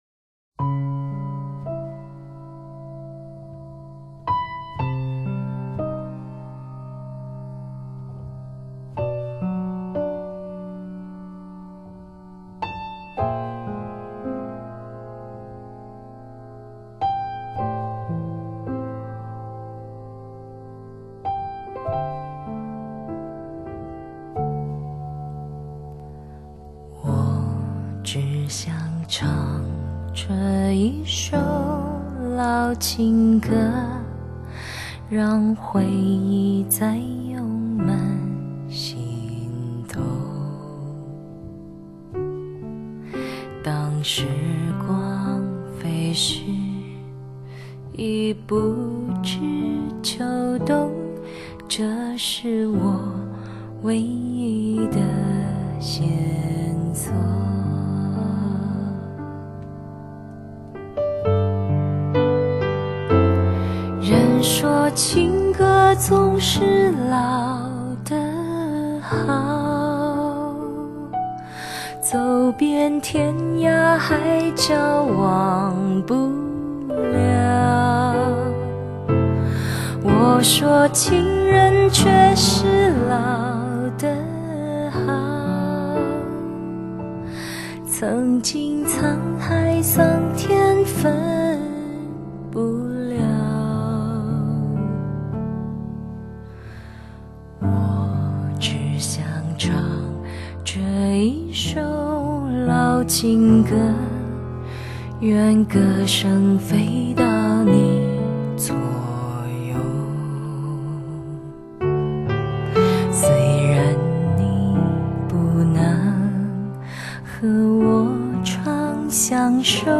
，将经典的华语歌曲改编为轻松慵懒的爵士曲风，录音也较“2V1G”更为自然。